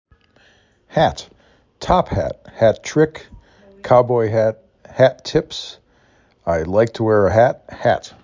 h a t
Local Voices
Nevada